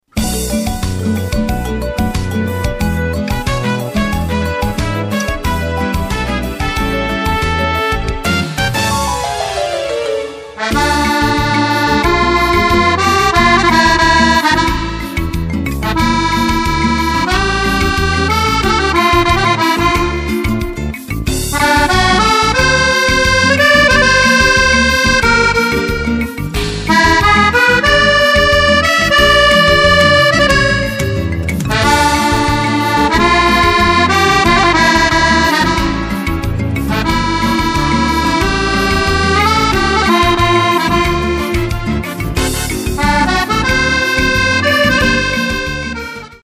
Bossa nova
extrait de l'album